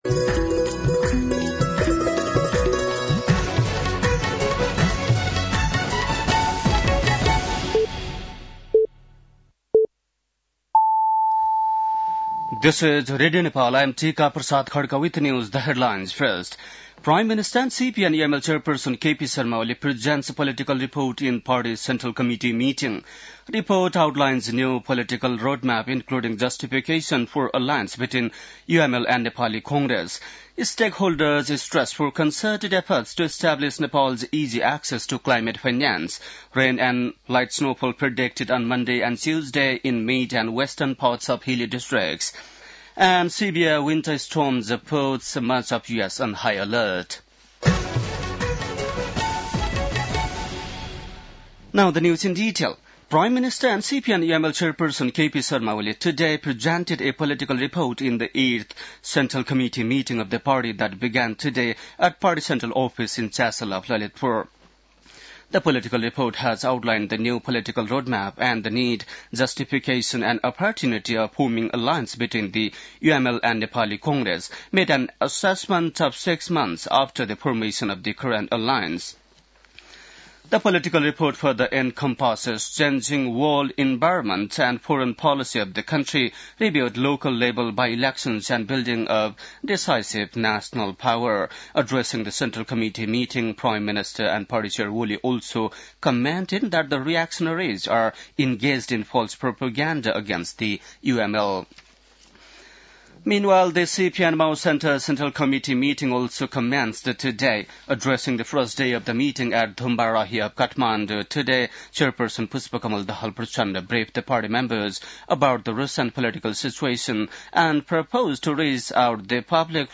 बेलुकी ८ बजेको अङ्ग्रेजी समाचार : २२ पुष , २०८१
8-PM-English-NEWS-9-21.mp3